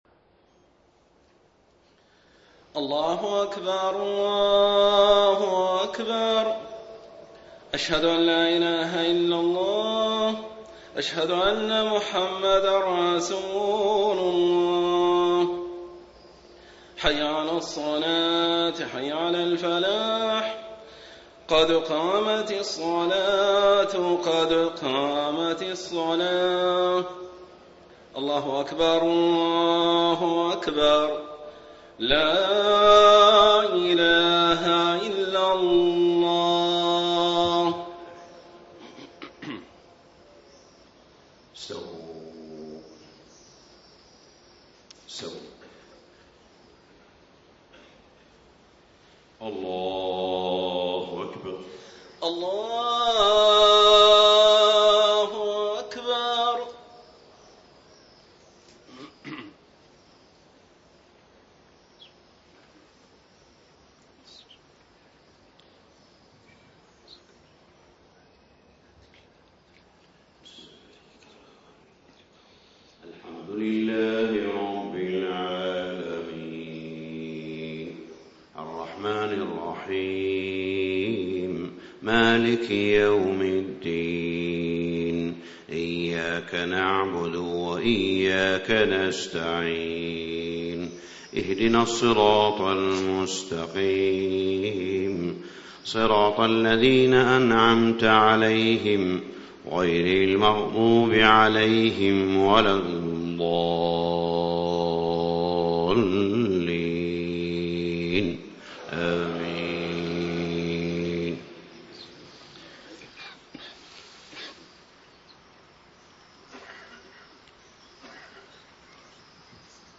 صلاة الفجر 3-5-1435 ما تيسرمن سورة ال عمران > 1435 🕋 > الفروض - تلاوات الحرمين